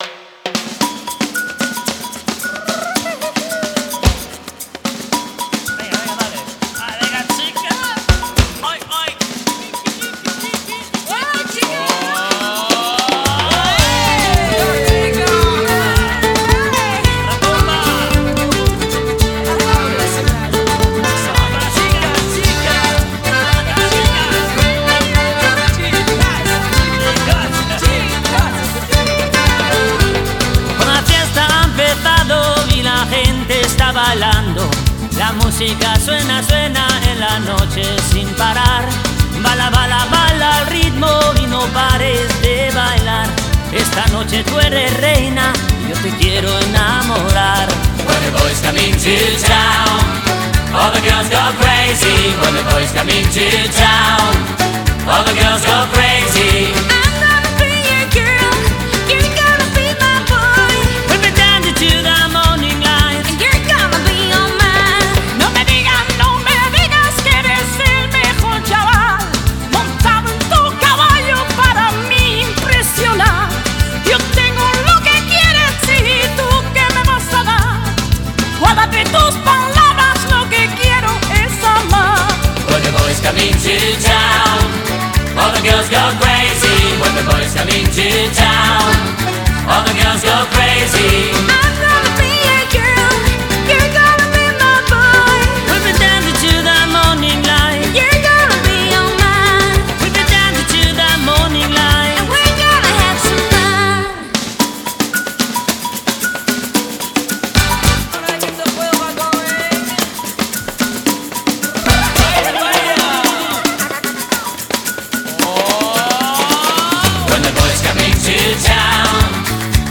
европейская фолк/рок группа